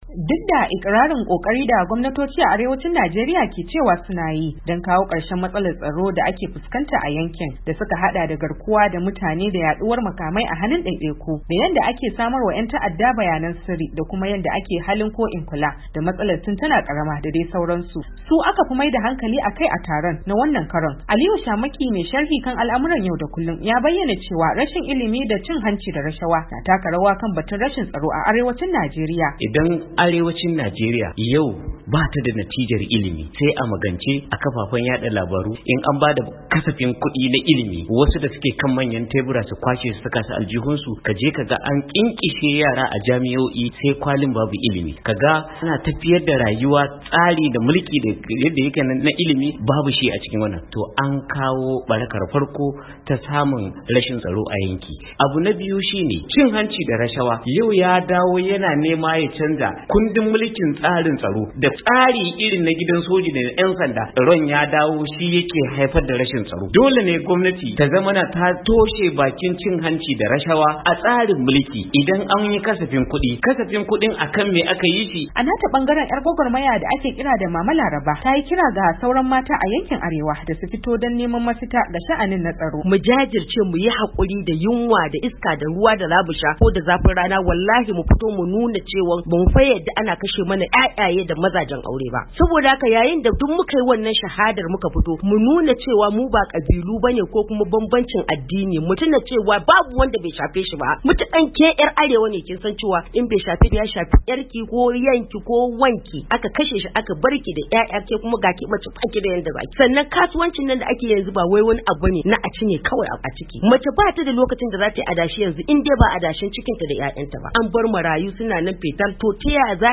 Iyaye mata, shugabannin al’umma da na kungiyoyi na daga cikin wadanda suka halarci wani zauren tattaunawa da Muryar Amurka ta shirya a ofishinta da ke Abuja babban birnin tarayyar Najeriya, a ci gaba da lalubo mafita dangane da matsalar tsaro da al’ummar kasar ke fustakanta.